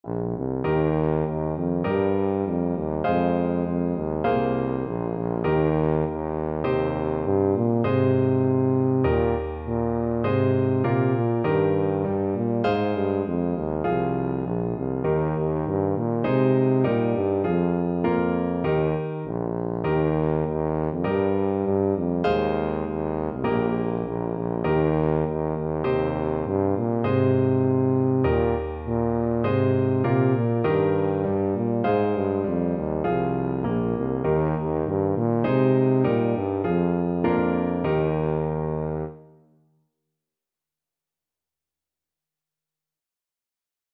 4/4 (View more 4/4 Music)
Moderato